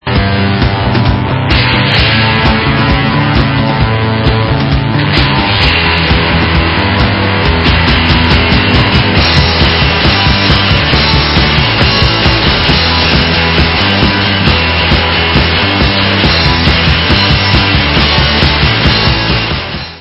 sledovat novinky v oddělení Rock/Punk